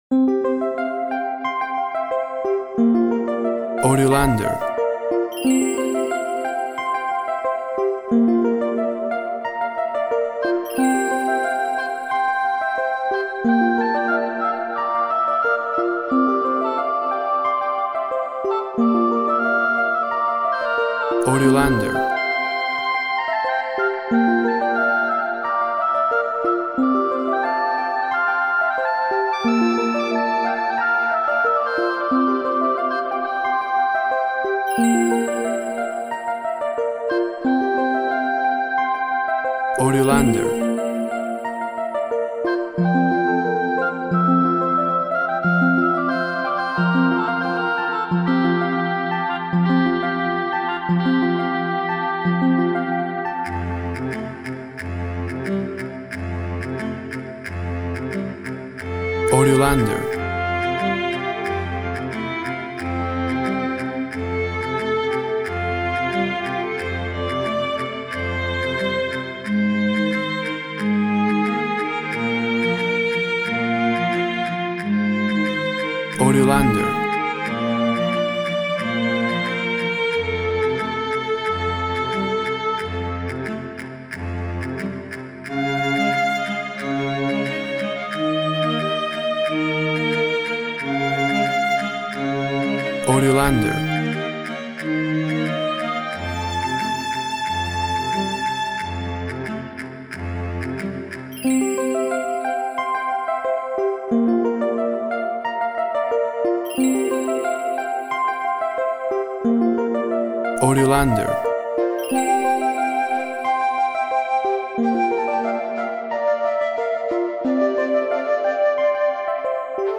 Harp, reed, flute, violin, and percussion in a playful mood.
Tempo (BPM) 90